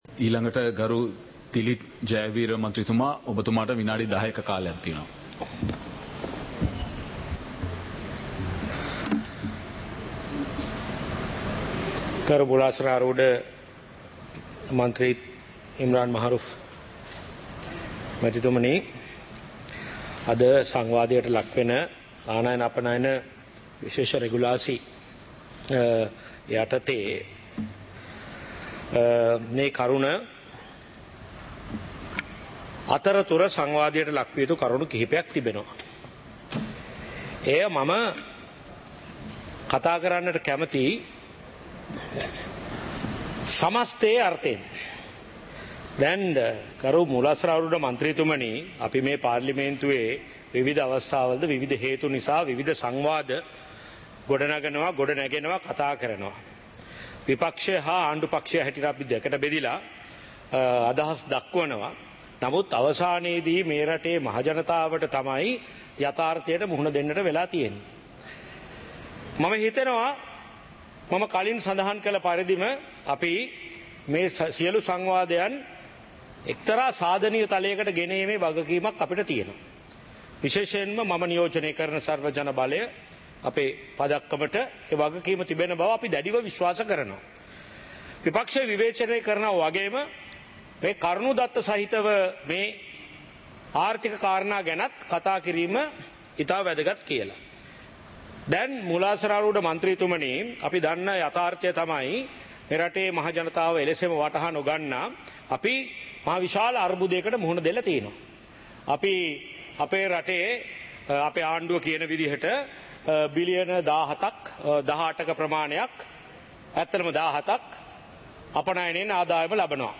நேரலை - பதிவுருத்தப்பட்ட